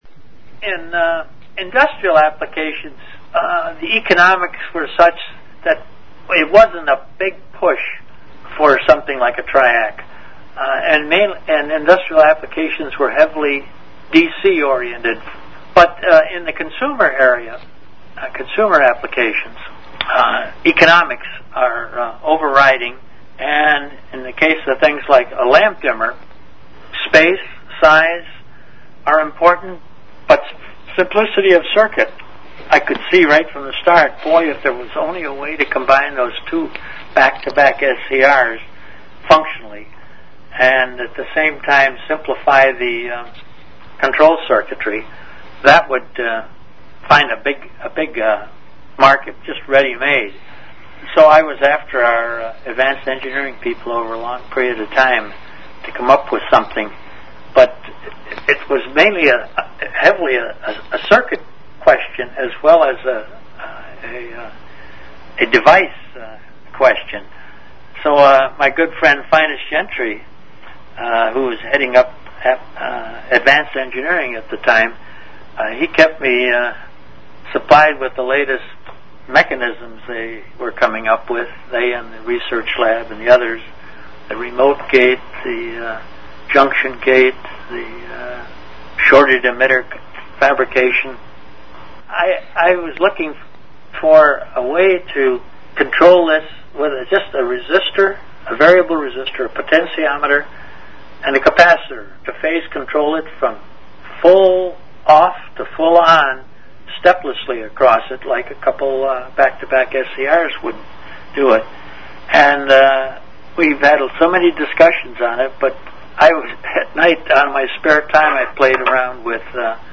from a 2005 Interview with